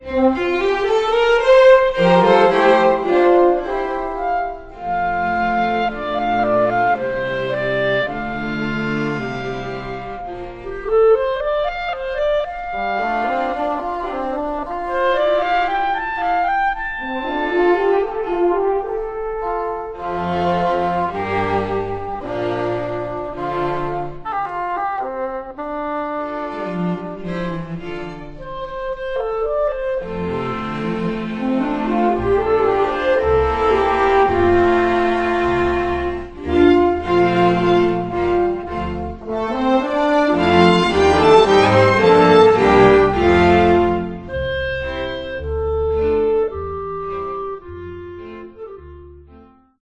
เชลโล
ดับเบิลเบส